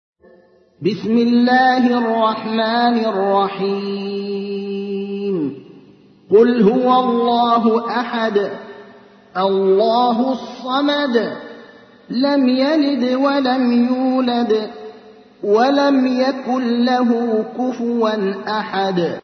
تحميل : 112. سورة الإخلاص / القارئ ابراهيم الأخضر / القرآن الكريم / موقع يا حسين